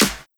• Clap Sound Clip G Key 04.wav
Royality free clap one shot - kick tuned to the G note. Loudest frequency: 3683Hz
clap-sound-clip-g-key-04-kHO.wav